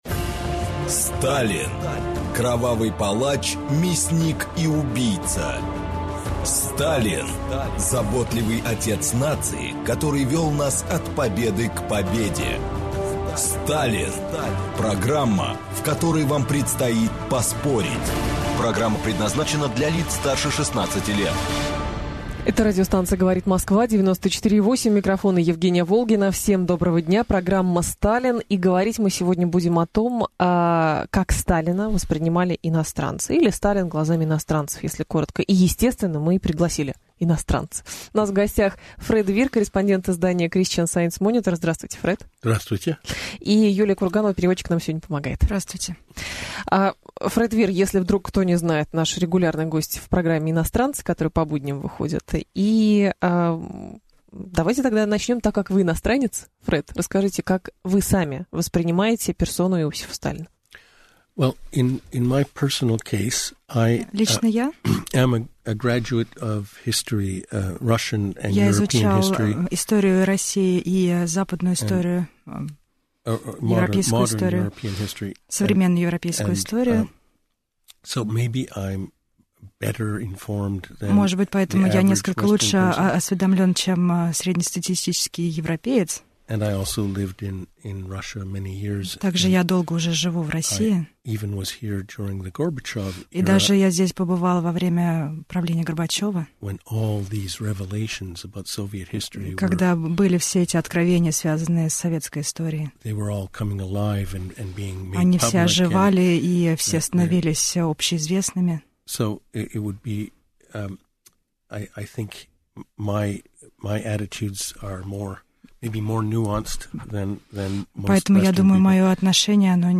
Аудиокнига Сталин глазами иностранцев | Библиотека аудиокниг